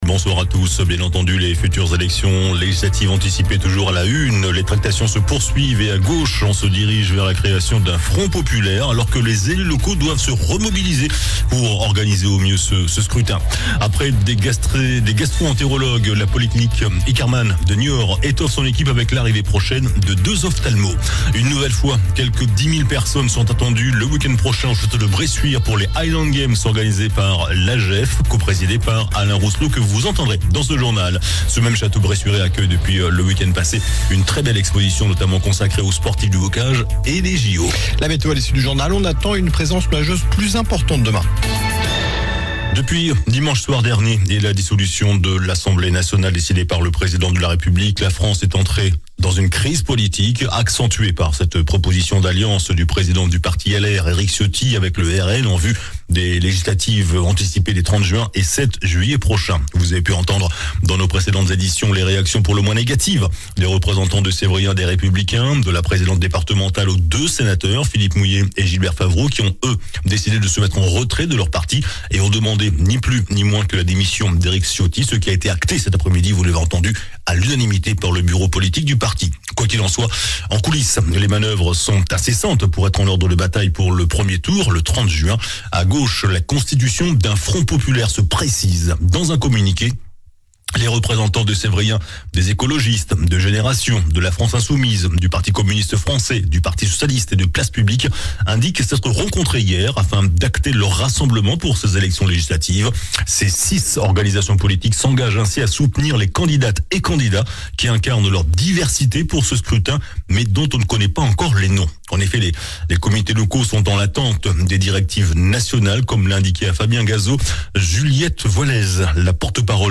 JOURNAL DU MERCREDI 12 JUIN ( SOIR )